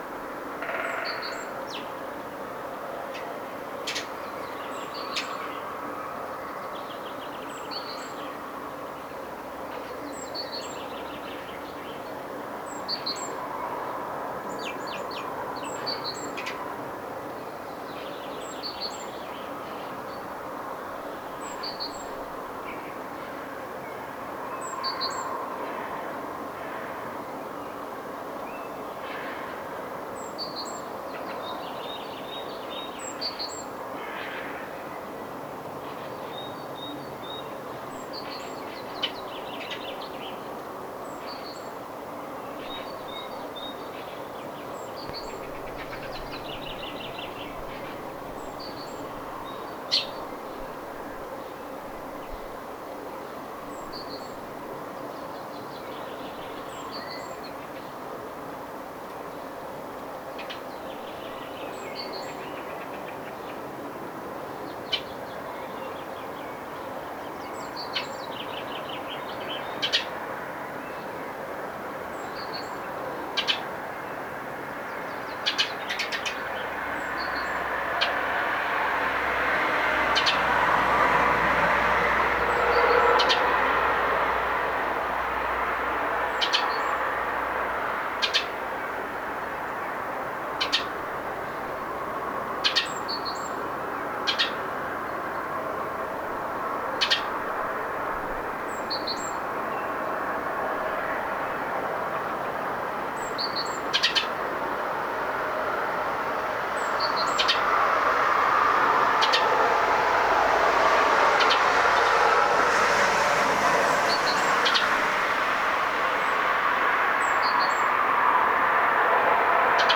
pikkuvarpunen ääntelee pöntön lähellä
pikkuvarpunen_aantelee_ponton_lahella.mp3